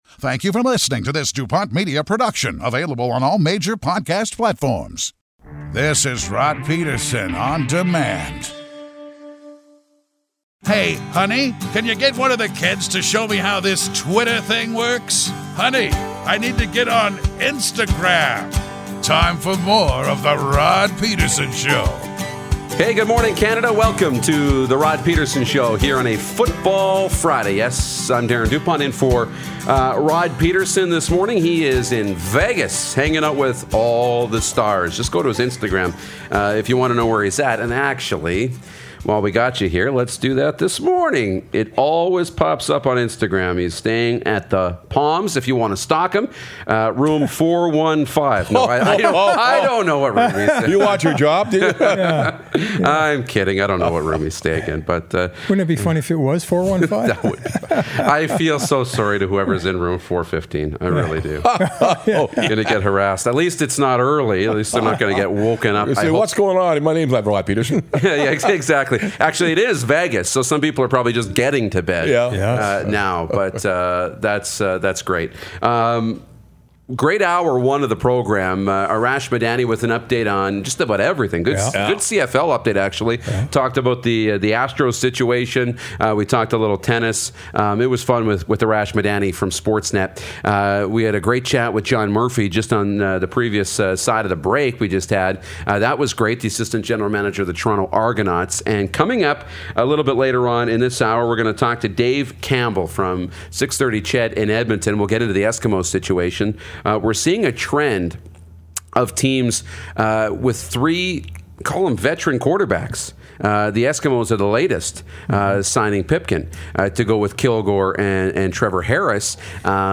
Canada’s only mid-morning sports talk show